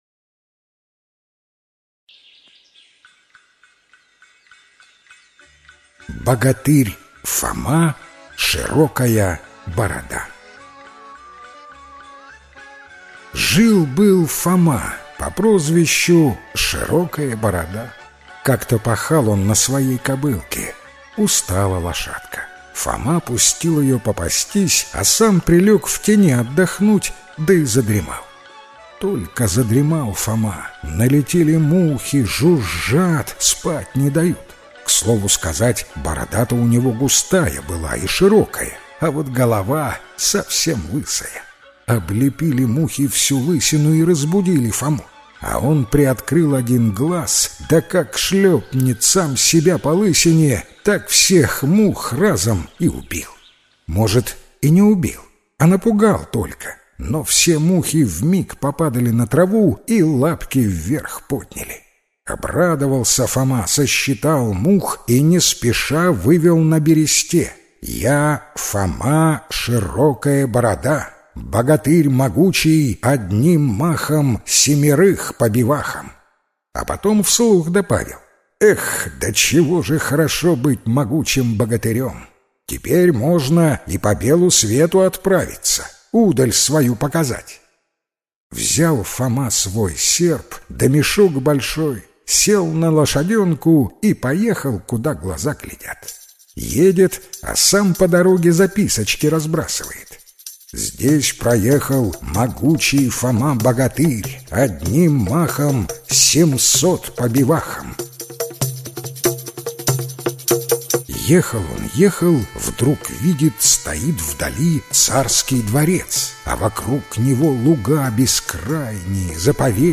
Богатырь Фома-широкая борода - белорусская аудиосказка - слушать онлайн